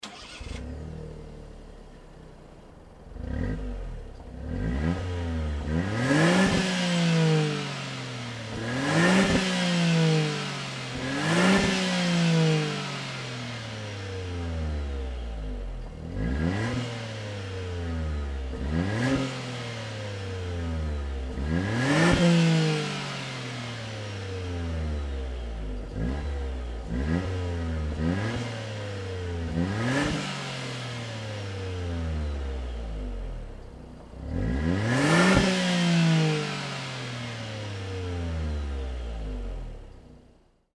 それぞれの排気音を収録してきたのでどうぞ・・
copen_la400-normal_acceleration.mp3